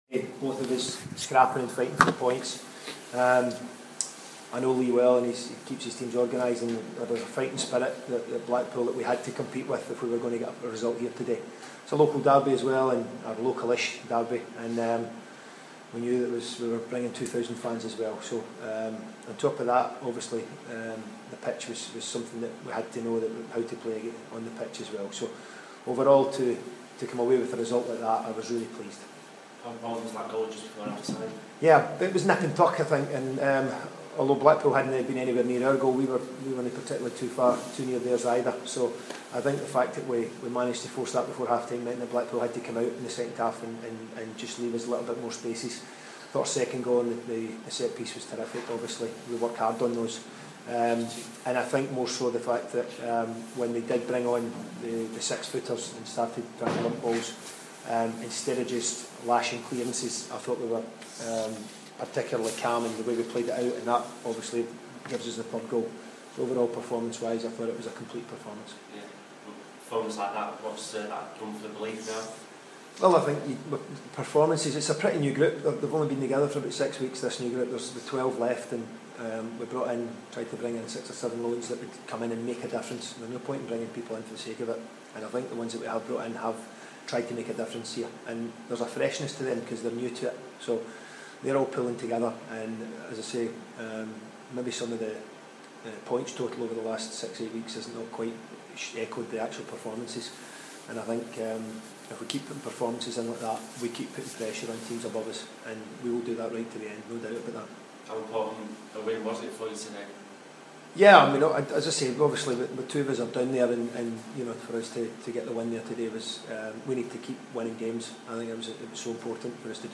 Have a listen to Wigan Athletic boss Malky Mackay give his thoughts after the 3-1 win at Blackpool.